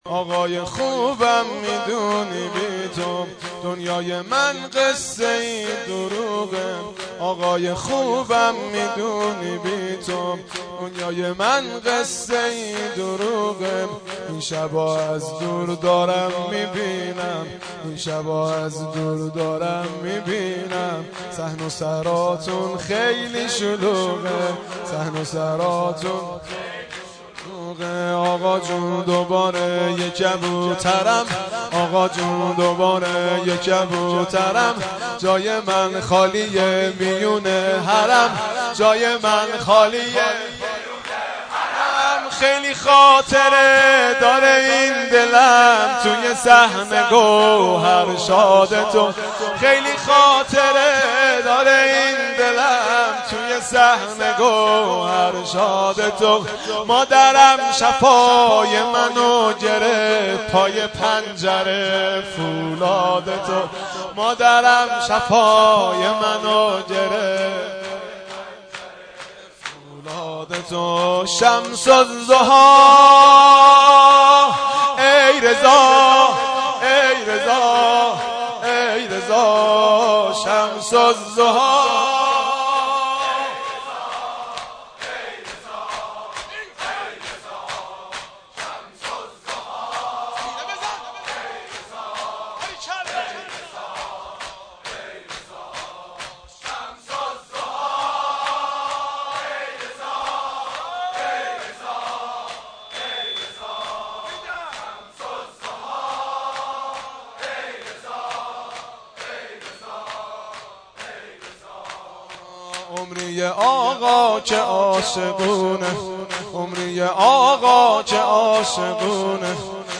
گلچین مداحی های شهادت امام رضا(ع)